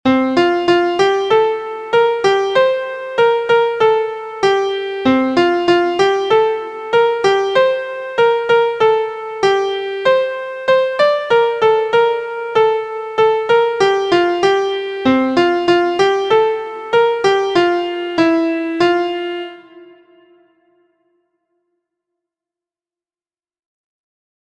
Practicing the ascending interval So/Do and the feeling of ending the first three lines on the second (Re).
• Origin: USA – Spanish Folk Song
• Key: F Major
• Time: 2/4
• Form: staves: AABa – song: AB verse/chorus
• Musical Elements: notes: quarter, eighth; rest: quarter; vocal slur
Collected from the Basque people in New Mexico, cir. 1932 – Sail Away, Locke